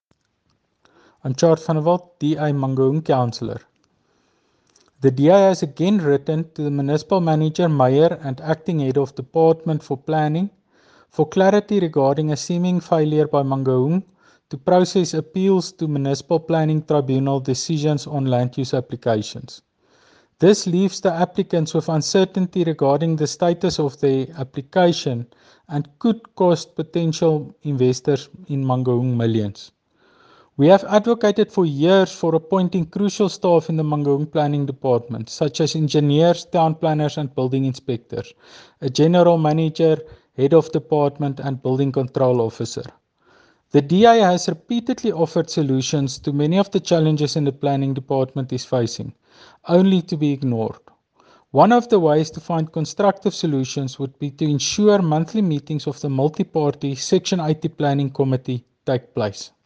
English and Afrikaans soundbites by Cllr Tjaart van der Walt and